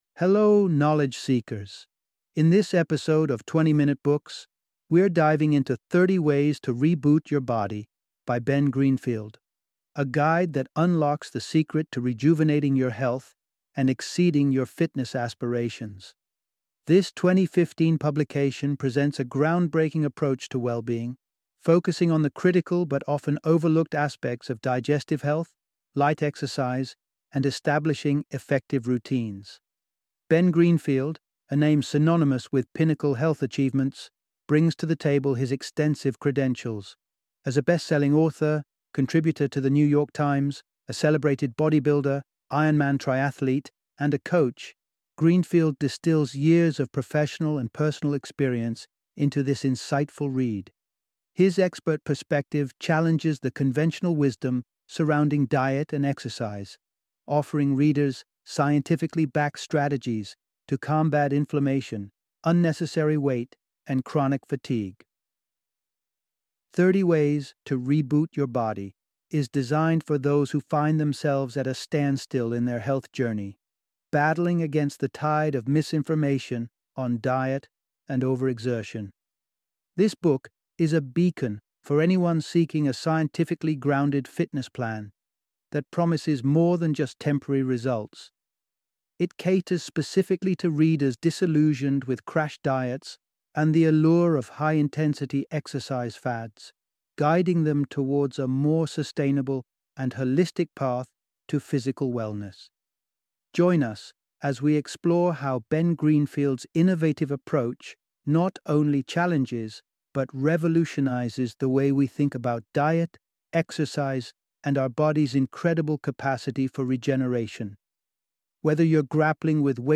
30 Ways to Reboot Your Body - Audiobook Summary